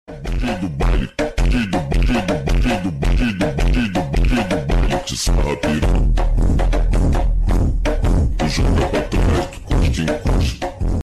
Хахахаха Sound Effects Free Download